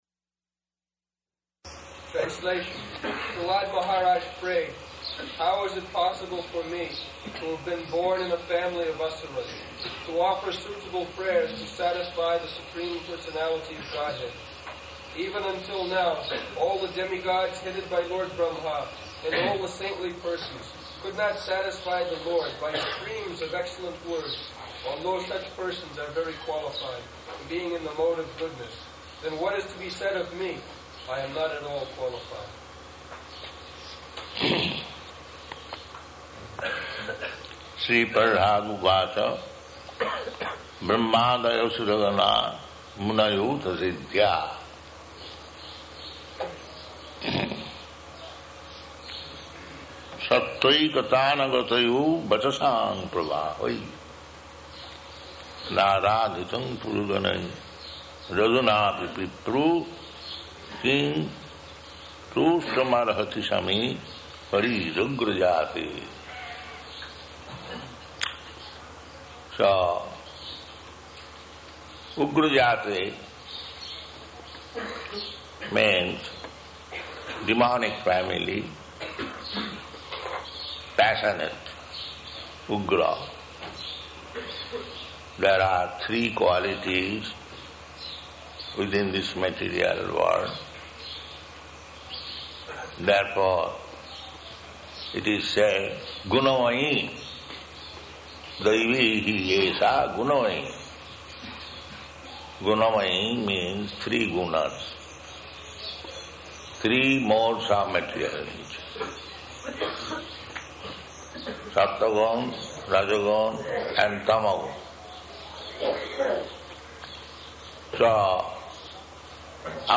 Location: Māyāpur
[loud electrical noise] [aside:] What is that?